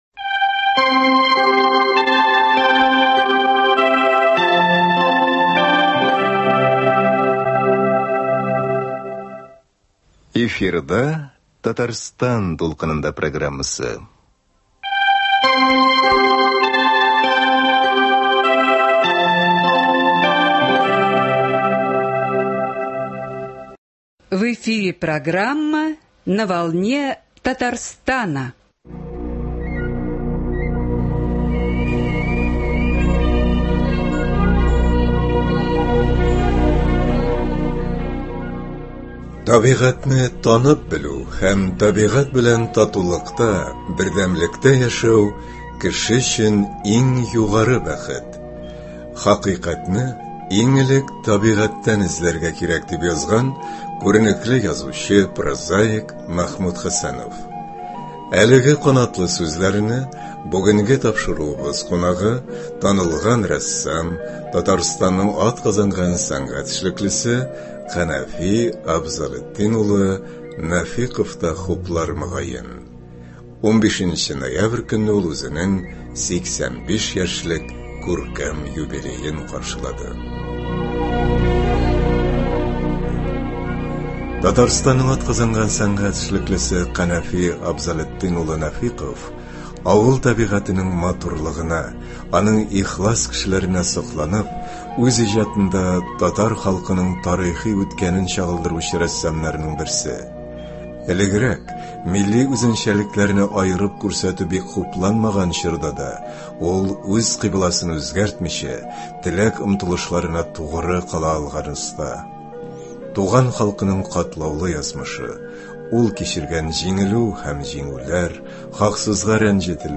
Аның белән кызыклы әңгәмә тыңлагыз. Тапшыруыбызның икенче өлешедә язучы Галимҗан Гыйльмановның “Ат күзләре” дигән парчасын тыңлый аласыз.